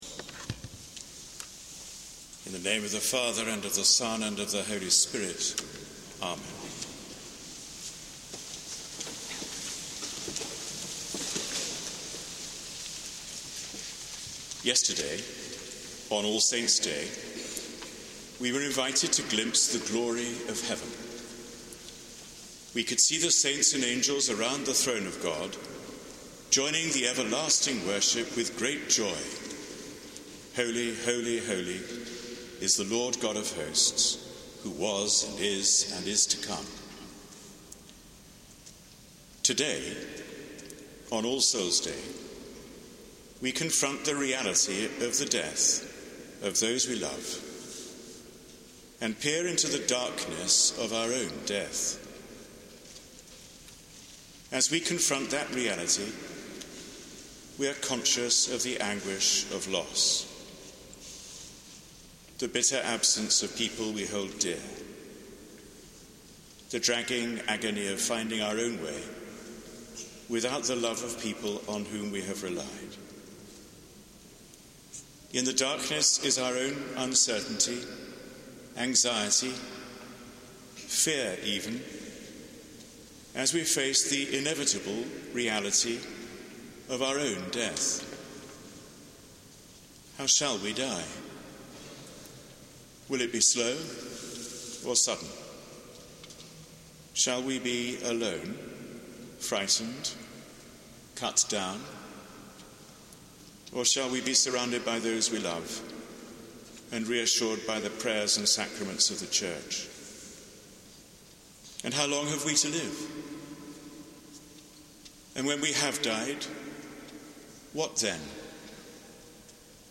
Sermon given at Sung Eucharist on All Soul's Day: Monday 2 November